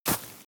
GravelStep3.wav